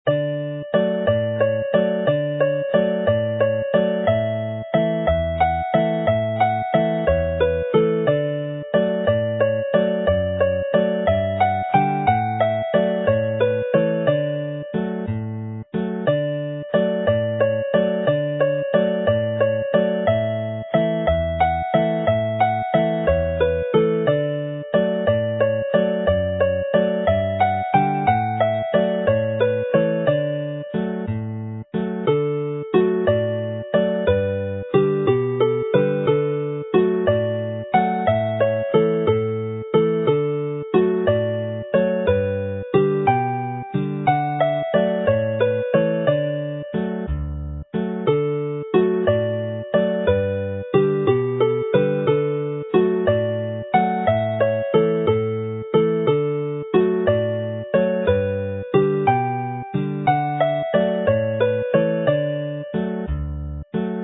Play slowly